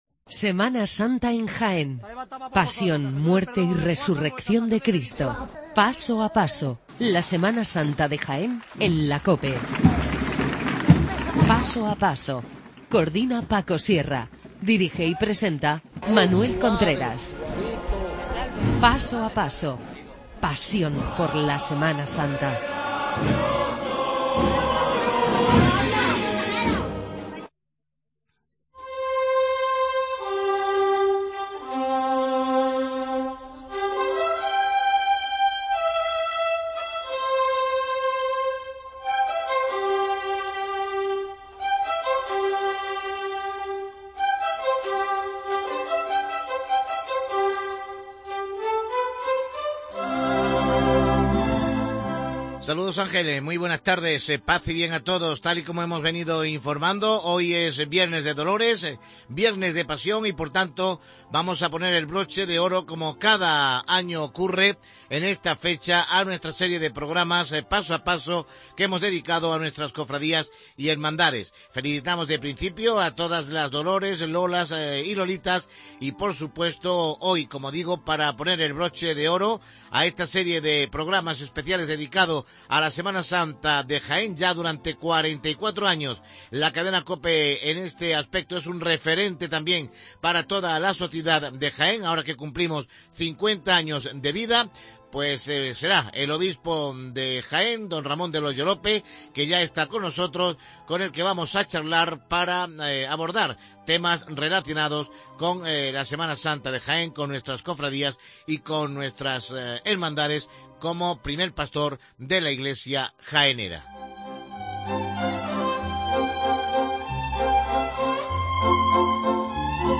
MONSEÑOR DEL HOYO LÓPEZ, INTERVIENE EN PASO A PASO